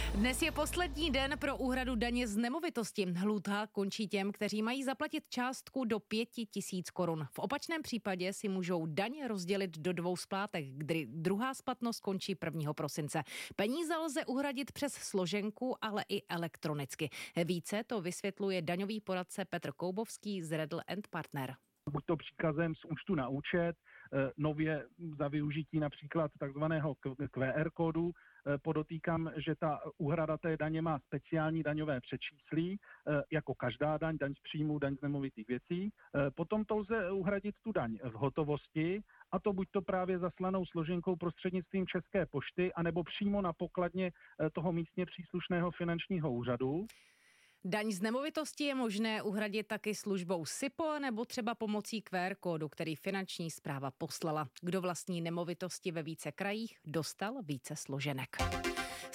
rozhovor k dani z nemovitých věcí